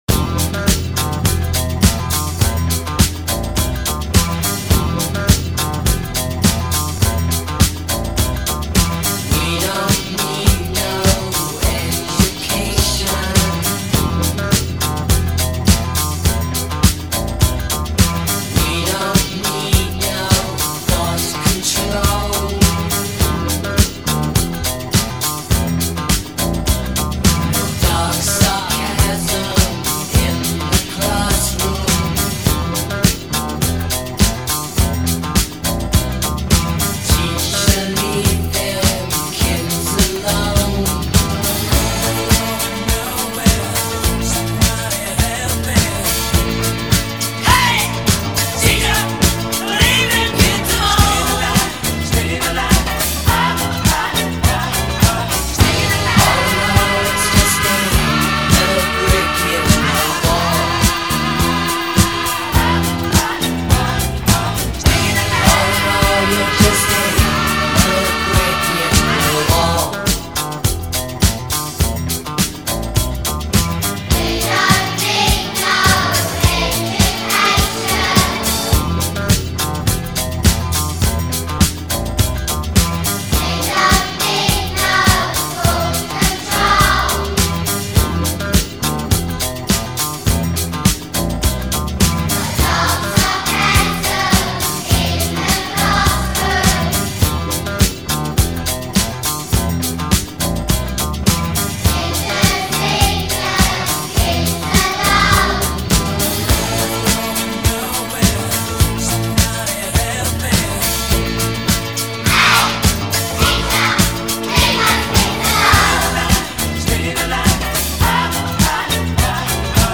Звучит очень необычно и забавно.